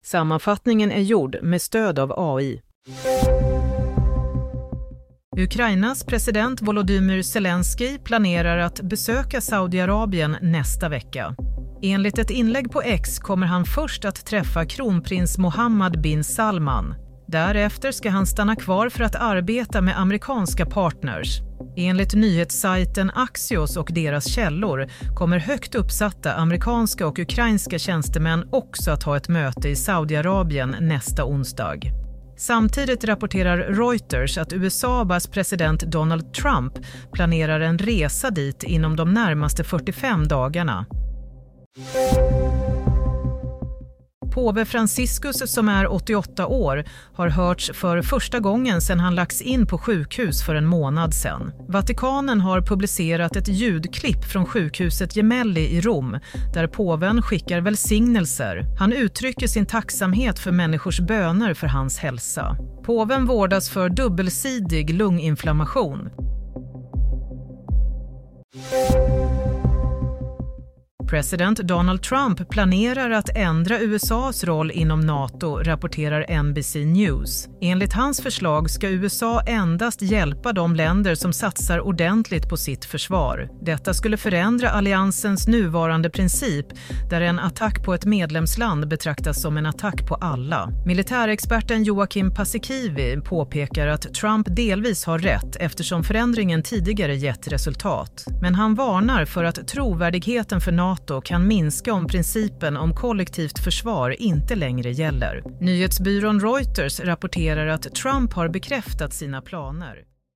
Nyhetssammanfattning - 6 mars 22:00